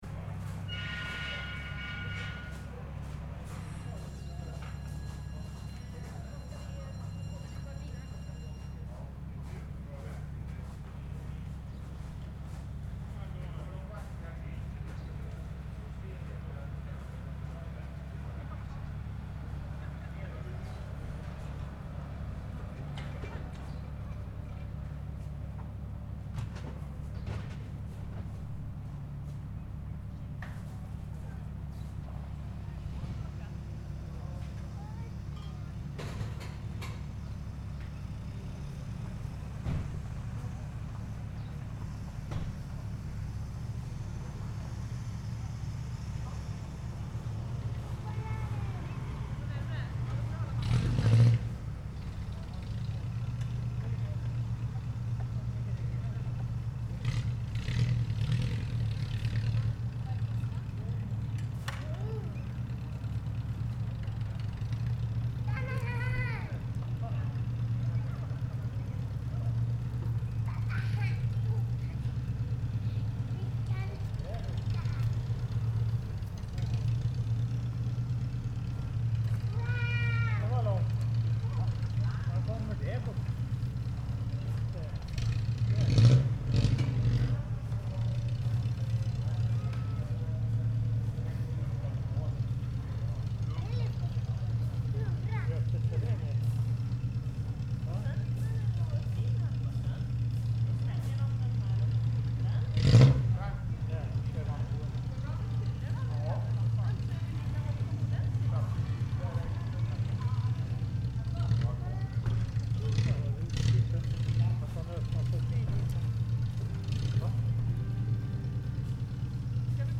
Göta Canal (lock): Opening of the water lock (2)
• Soundscape
• Water
• Norsholm
• Boat